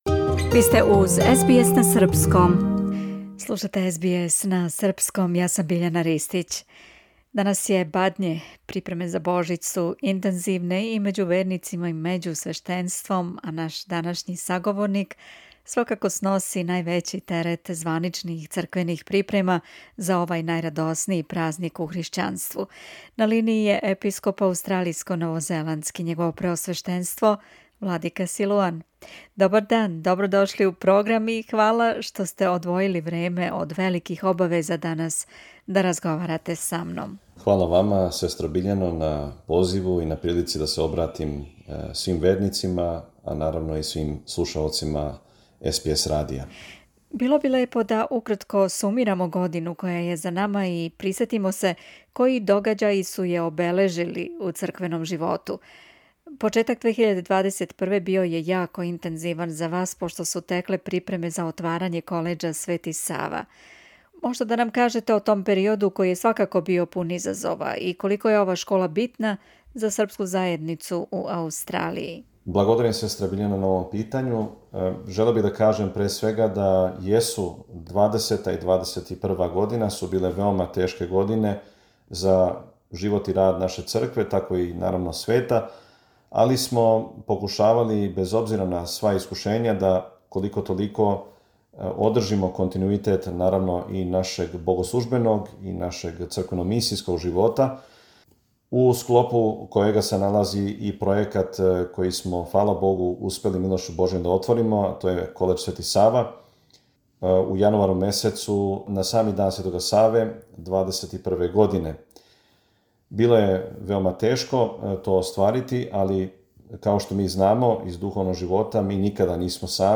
Епископ Митрополије аустралијско-новозеландске, његово преосвештенство г. Силуан, у интервјуу за СБС радио осврнуо се на протеклу годину, рекао нам је више о припемама за Бадње и Божић у српским црквама широм Аустралије и такође послао поруке љубави и мира свим слушаоцима.